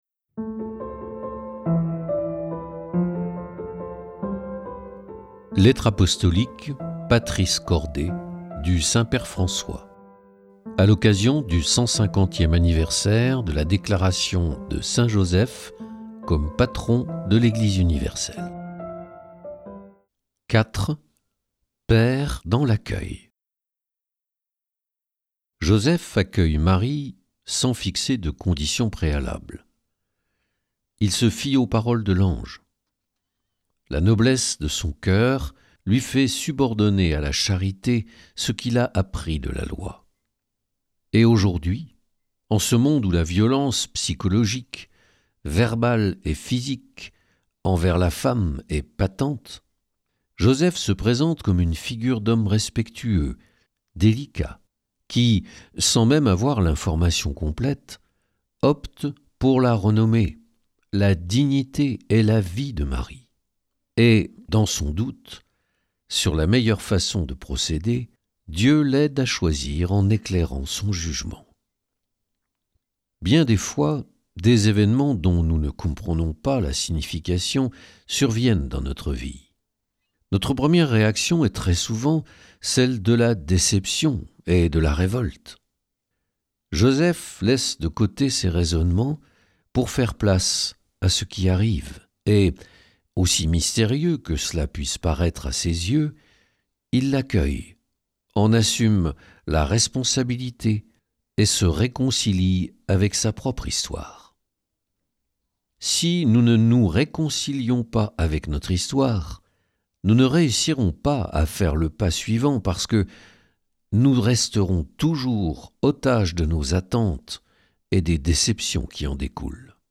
Patris corde – livre audio numérique